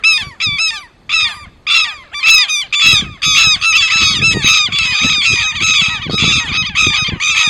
Quero-quero (Vanellus chilensis)
Nome em Inglês: Southern Lapwing
Localidade ou área protegida: Perilago Termas de Río -hondo
Condição: Selvagem
Certeza: Fotografado, Gravado Vocal